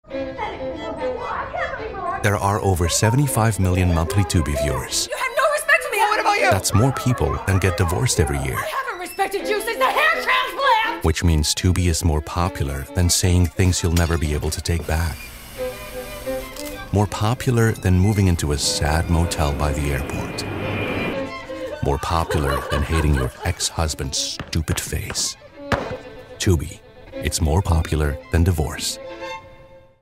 Male
Approachable, Assured, Authoritative, Bright, Character, Confident, Conversational, Cool, Corporate, Deep, Energetic, Engaging, Friendly, Funny, Gravitas, Natural, Posh, Reassuring, Sarcastic, Smooth, Soft, Upbeat, Versatile, Wacky, Warm, Witty
Microphone: Sennheiser MKH416 and Neumann TLM103
Audio equipment: Studiobricks One Custom, UA Apollo Twin